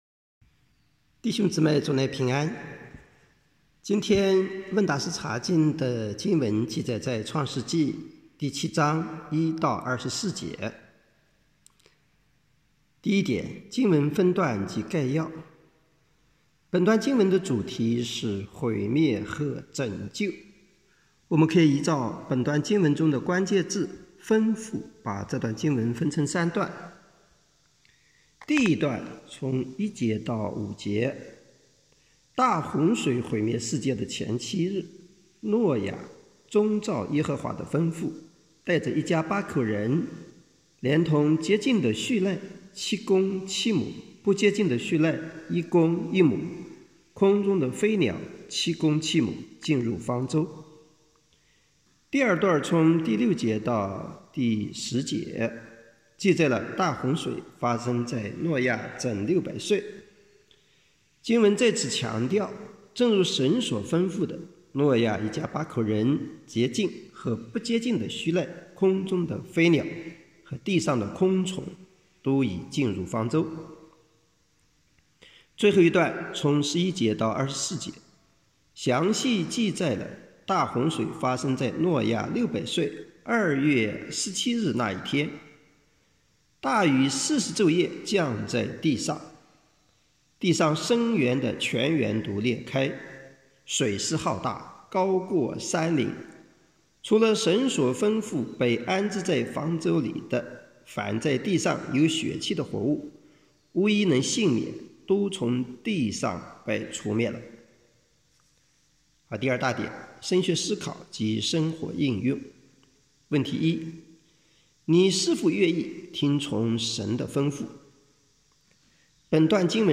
课程音频：